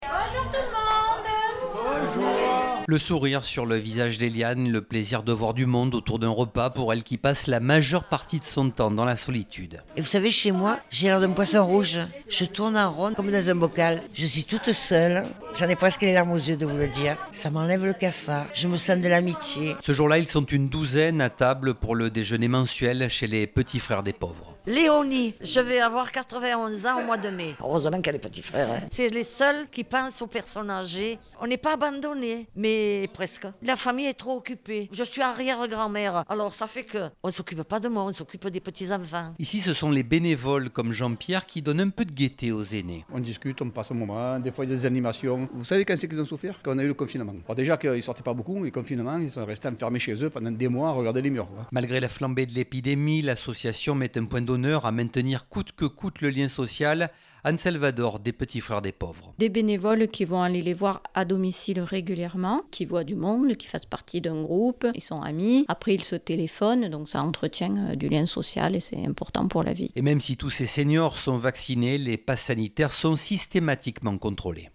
Reportage à Marseille